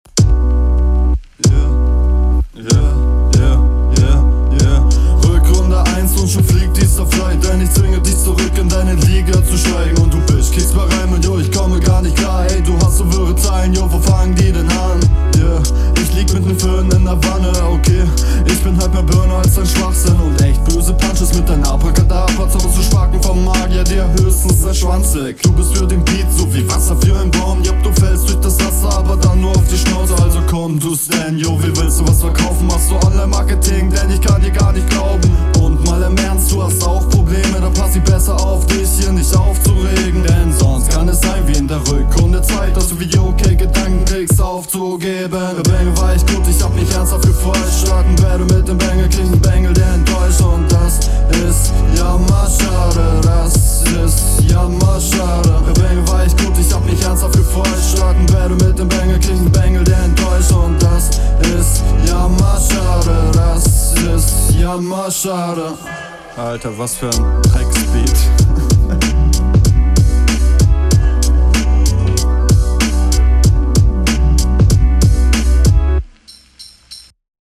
Der flow ist super clean, das mix mastering ist …
find schonmal nicht so nice wie du den beat berappst, klang bei deinem gegner cooler.